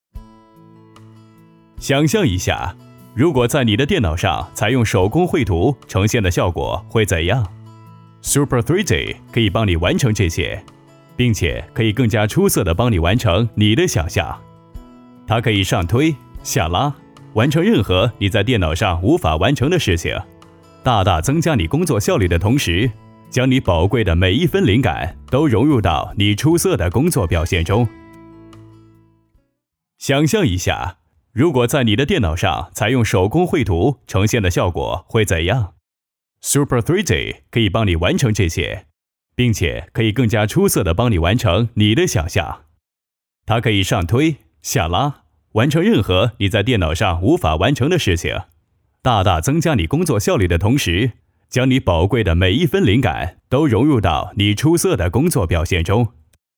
14 男英28_外语_国内英语_广告SUPER3D绘图 男英28
男英28_外语_国内英语_广告SUPER3D绘图.mp3